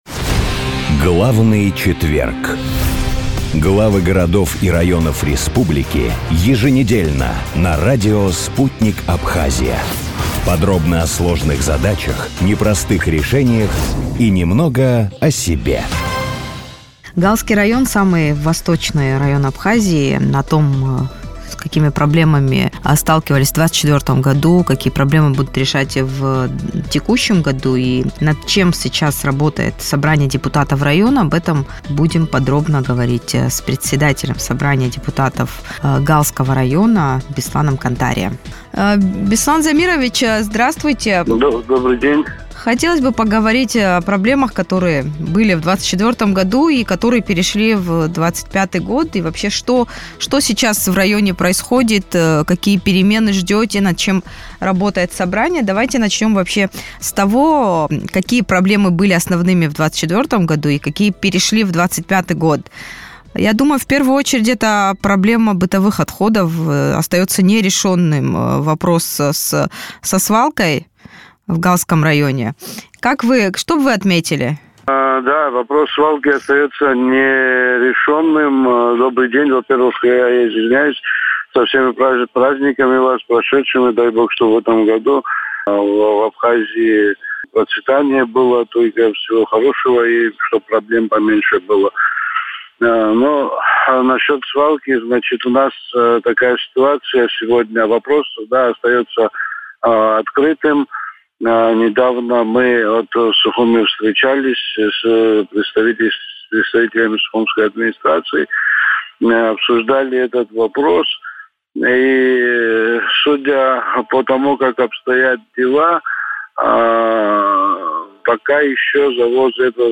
Депутат Парламента Абхазии Беслан Халваш в интервью радио Sputnik проанализировал ситуацию с налоговыми поступлениями в 2024 году.